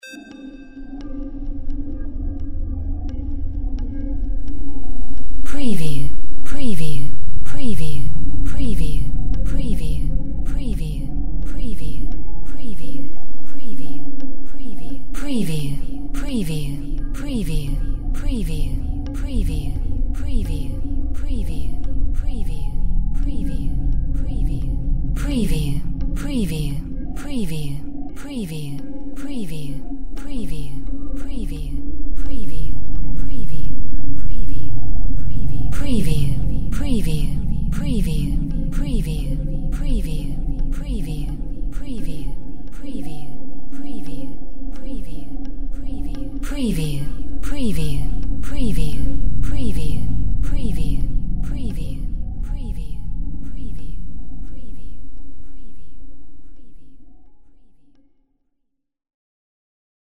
Drone Lo Morph Pulse FX 02
Stereo sound effect - Wav.16 bit/44.1 KHz and Mp3 128 Kbps
previewDRONE_LO_MORPH_PULSEFX_WBSD02.mp3